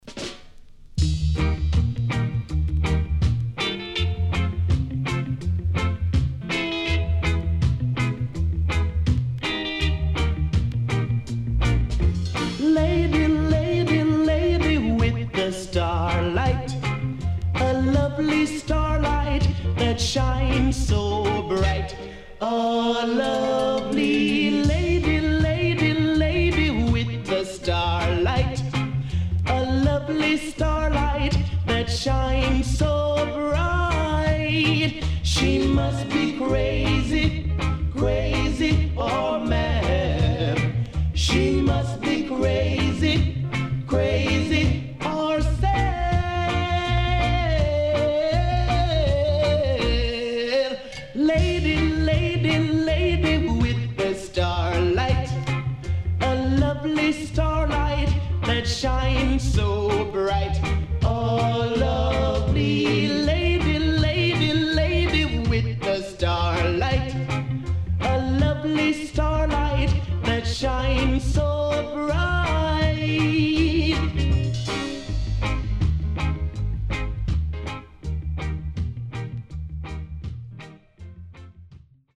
Rocksteady Classics